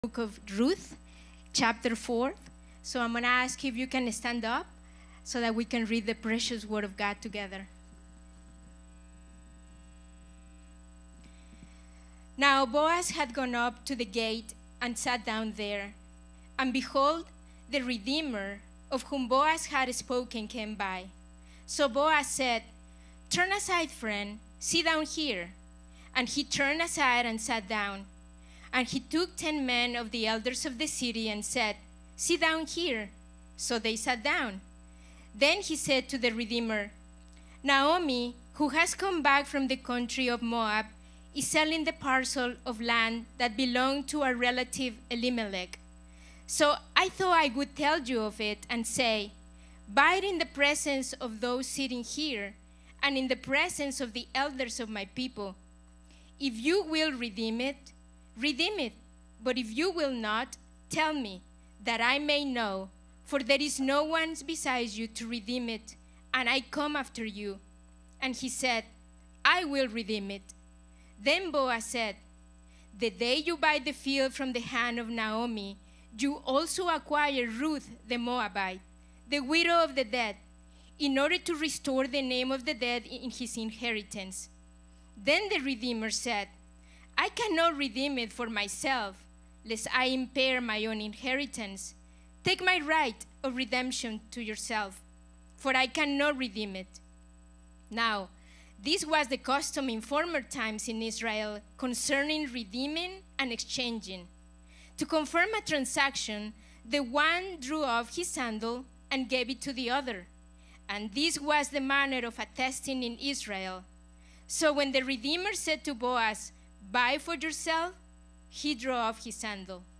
Ruth Service Type: Sunday 10am « Ruth Part 4